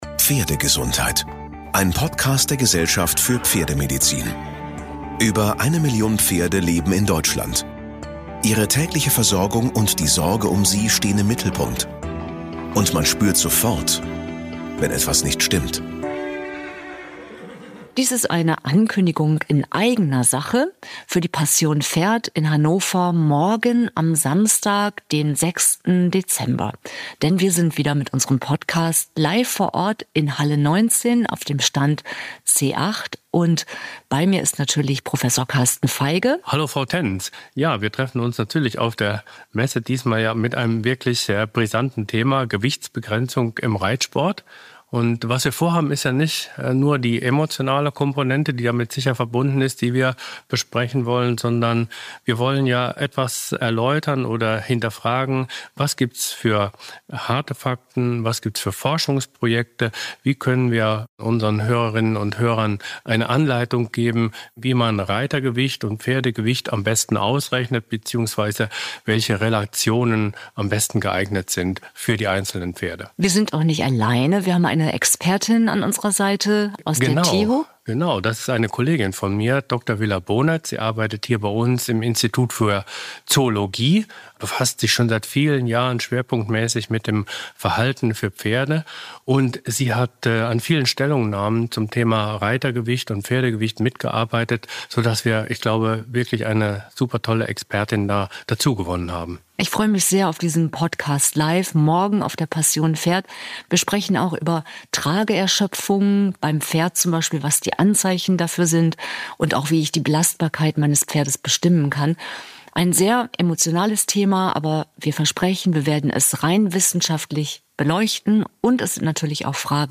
Ankündigung: Gewichtsbegrenzung im Reitsport